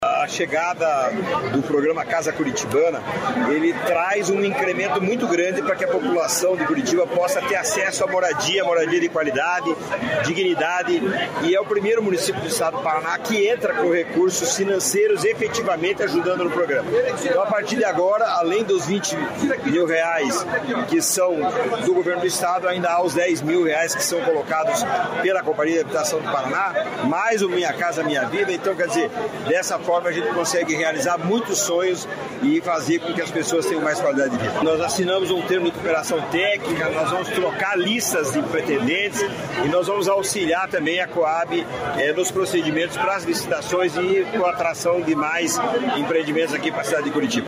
Sonora do presidente da Cohapar, Jorge Lange, sobre o Casa Curitibana